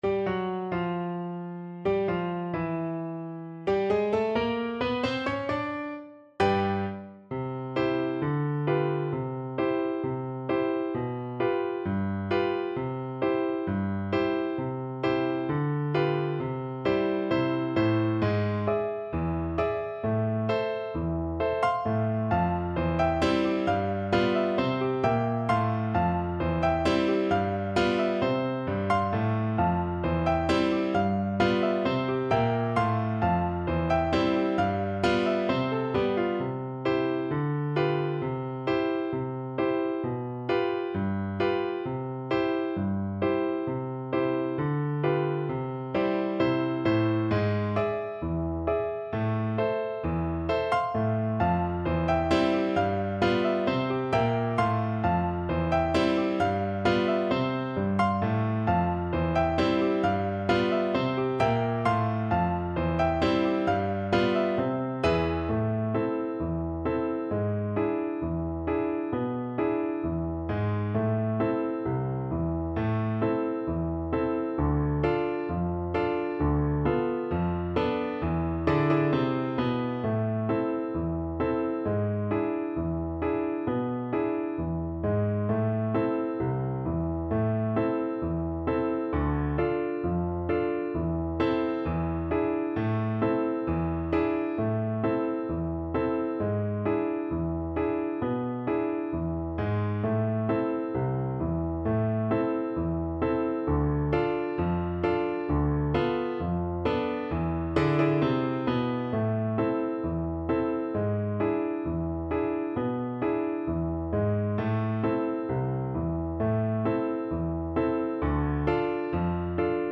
4/4 (View more 4/4 Music)
= 132 Allegretto ma non troppo
Jazz (View more Jazz Recorder Music)